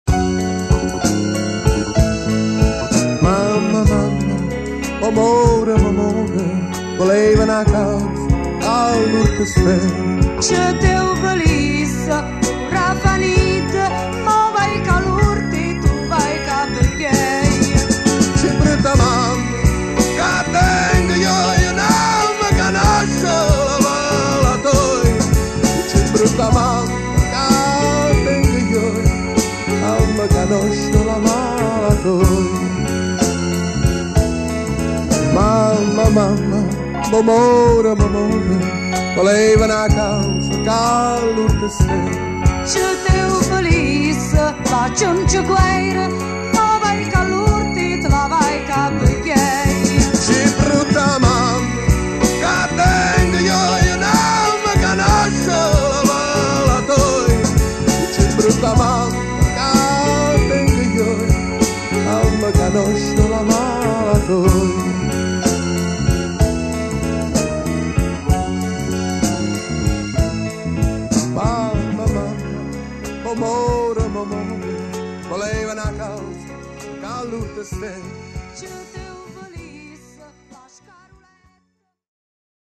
oggi viene riproposto in versione rimasterizzata.